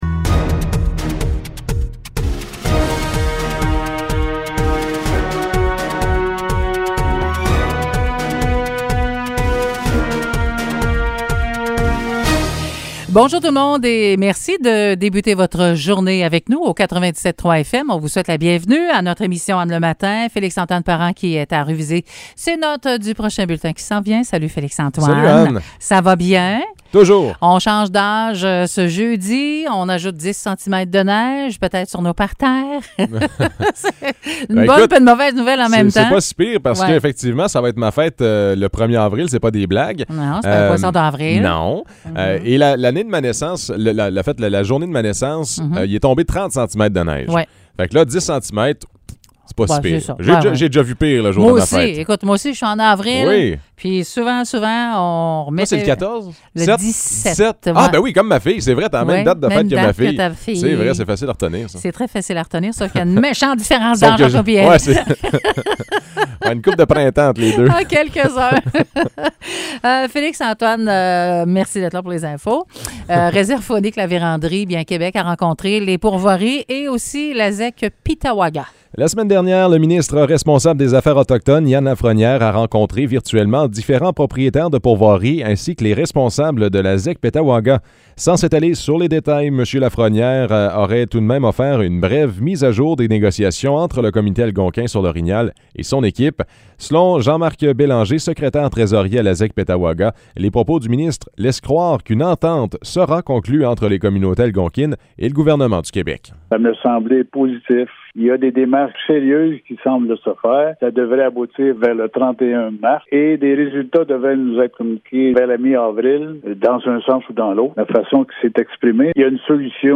Nouvelles locales - 30 mars 2021 - 9 h